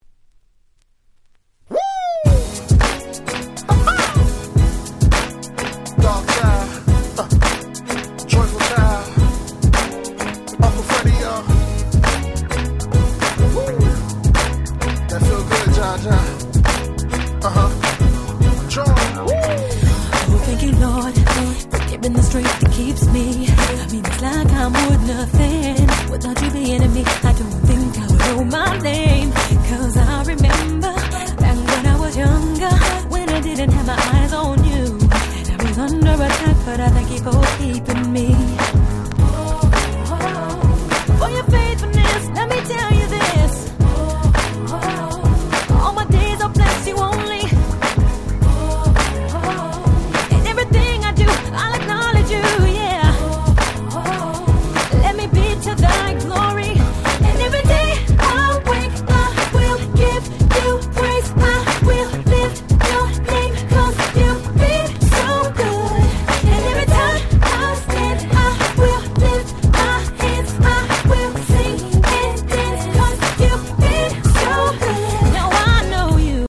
06' Nice R&B !!